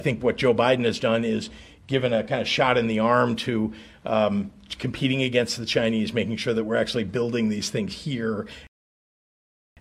Governor Pritzker joined the Illinois Manufacturers Association and representatives from the EV industry Tuesday for the first ever “EV Showcase Day” in Springfield.  Governor Pritzker gives President Joe Biden credit for his EV Policies.